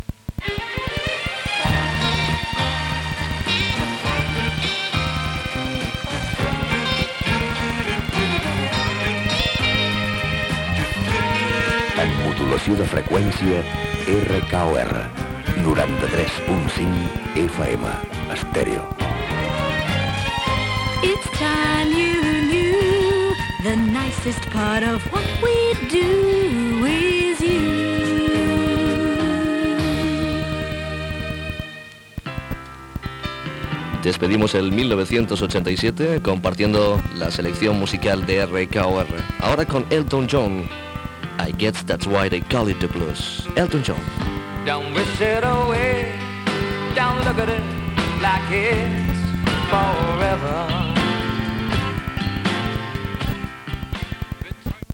Indicatiu i tema musical.